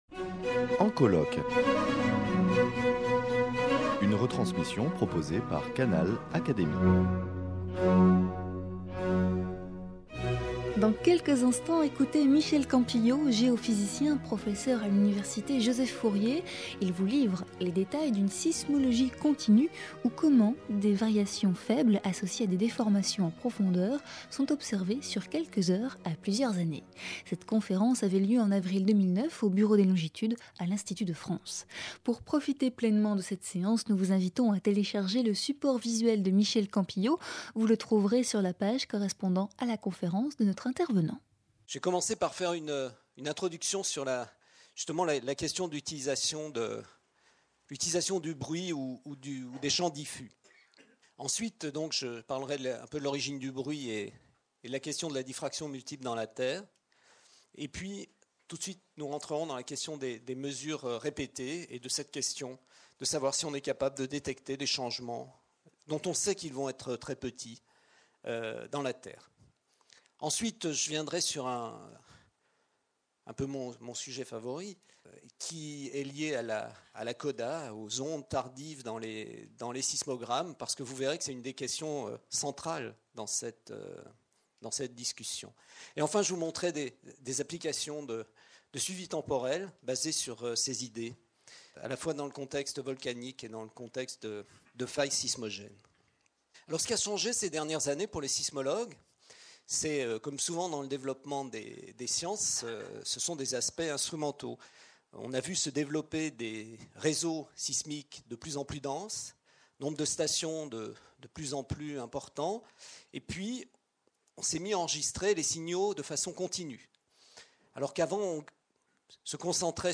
Cette conférence avait lieu au Bureau des longitudes, à l’Institut de France.